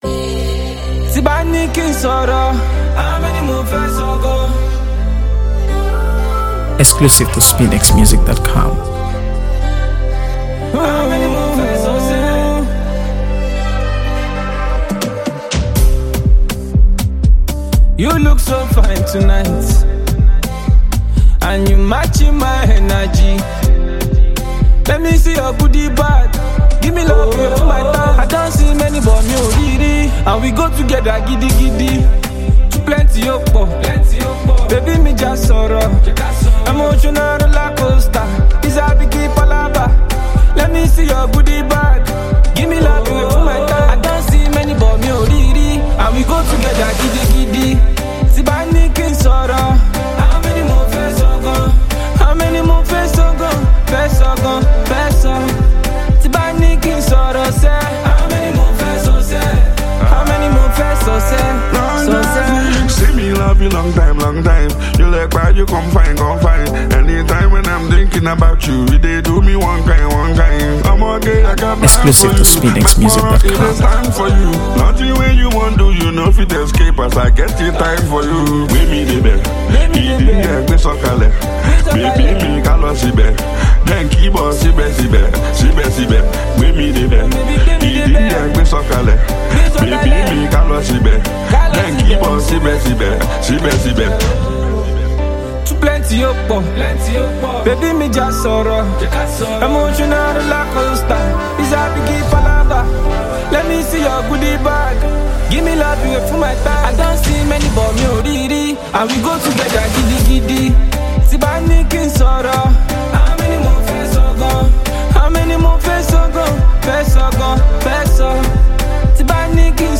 AfroBeats | AfroBeats songs
Full of a special mix of Afrobeats, Afro-pop